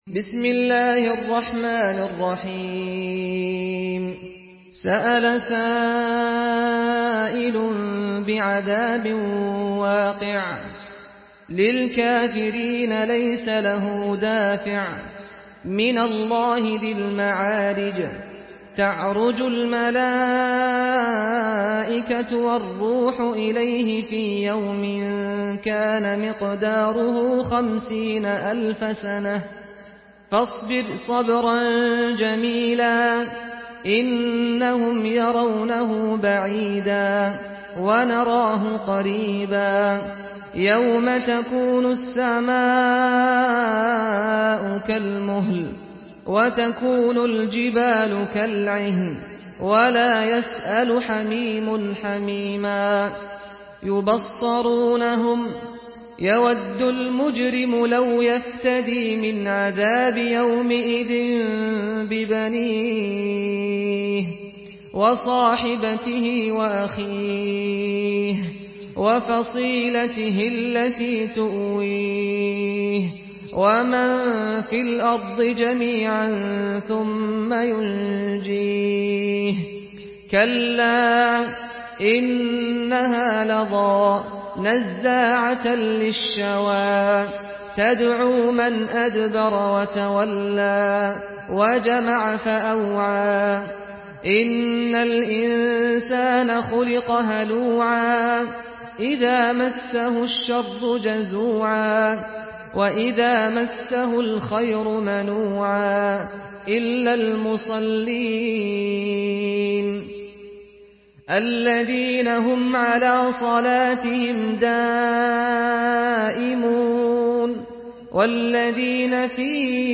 سورة المعارج MP3 بصوت سعد الغامدي برواية حفص
مرتل